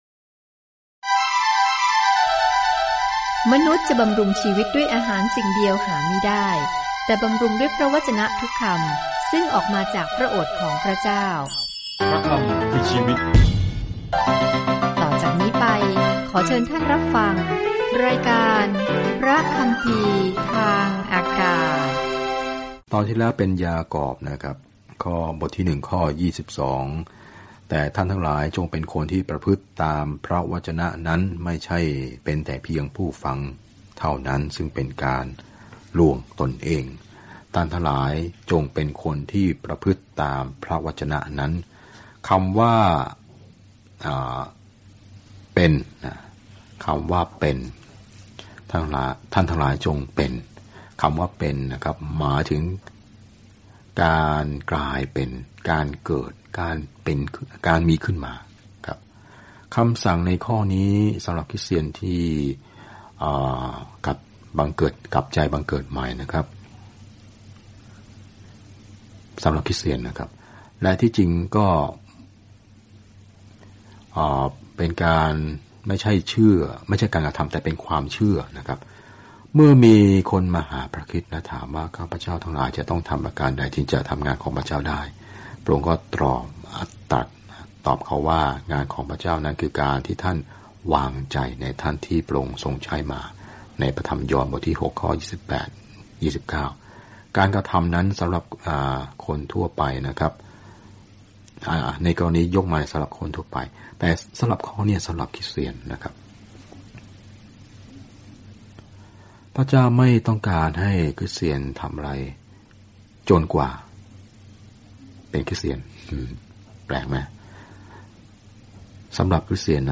เดินทางผ่านเจมส์ทุกวันในขณะที่คุณฟังการศึกษาด้วยเสียงและอ่านข้อที่เลือกจากพระวจนะของพระเจ้า